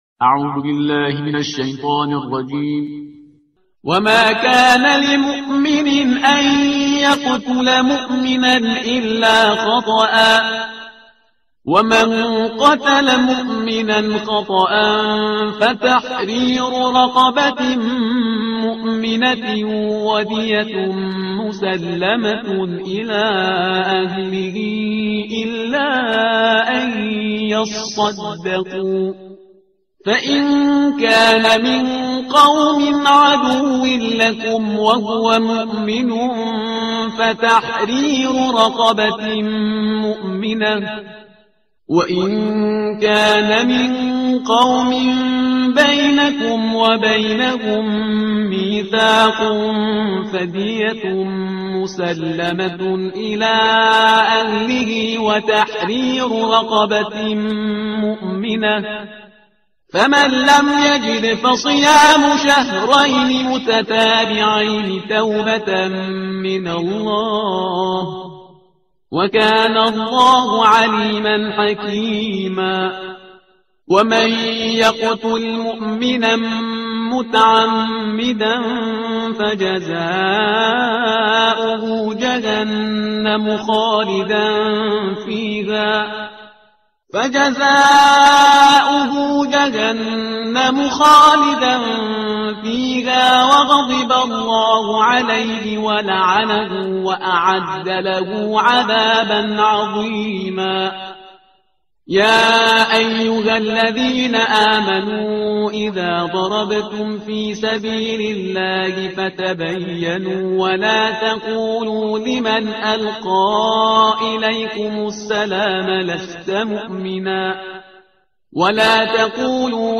ترتیل صفحه 93 قرآن با صدای شهریار پرهیزگار